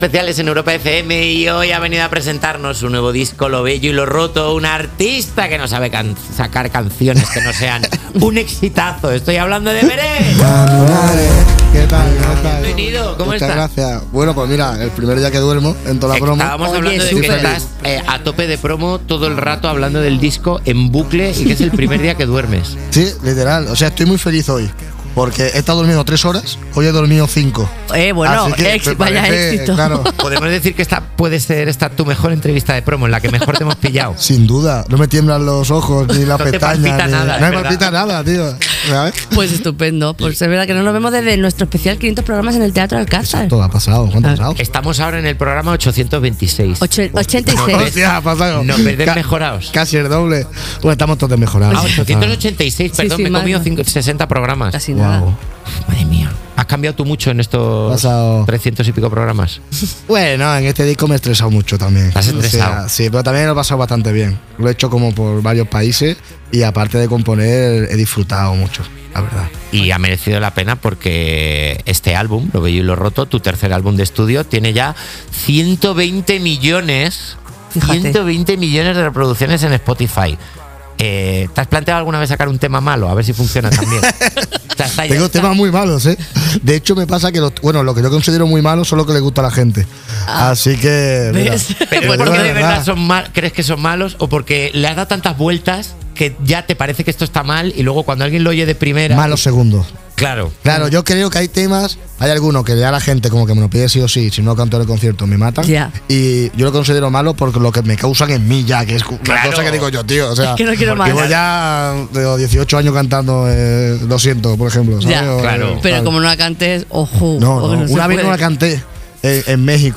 La entrevista de Beret en Cuerpos especiales